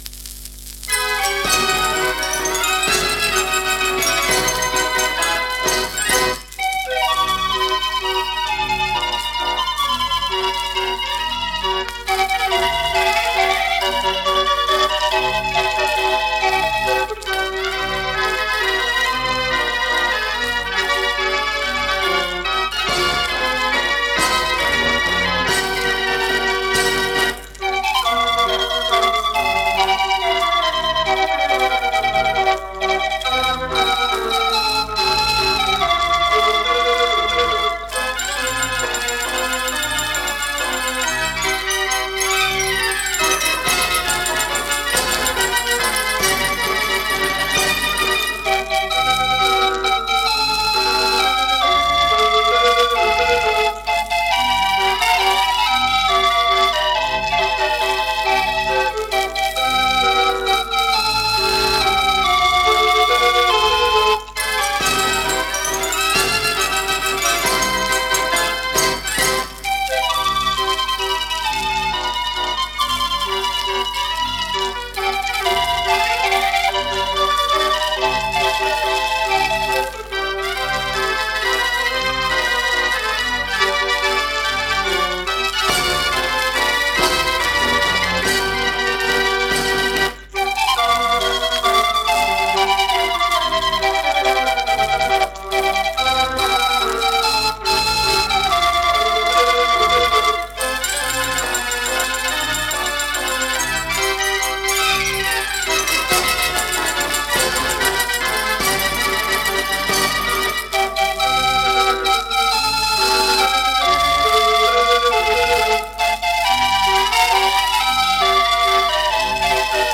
Uitvoerend orgel Draaiorgel “De Arabier”
Formaat Grammofoonplaat, vermoedelijk 78 toeren
Bijzonderheden Blauw Philips-label, beide zijden met opname van hetzelfde straatorgel
Traditioneel, arrangement Tom Erich